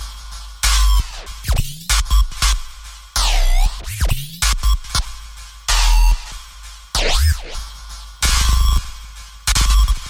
Tag: 95 bpm Electronic Loops Drum Loops 1.70 MB wav Key : Unknown